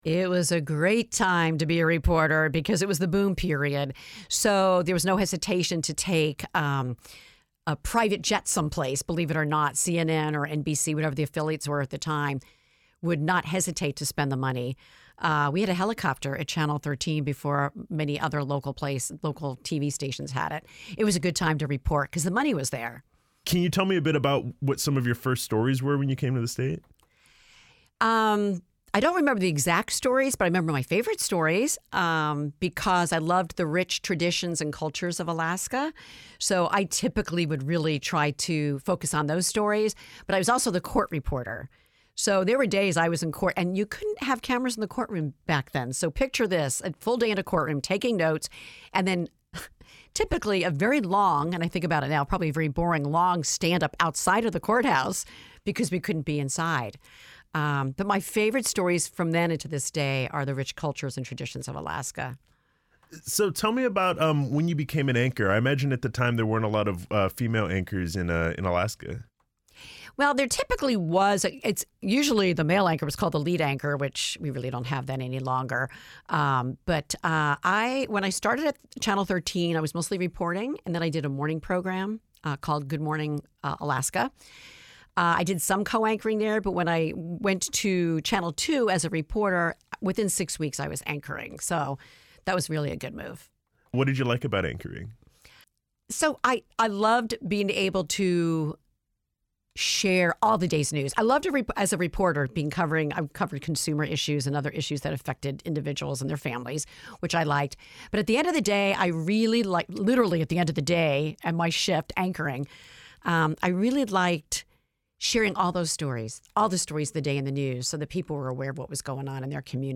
The interview was edited lightly for clarity and length.